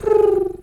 Animal_Impersonations
pigeon_2_call_calm_09.wav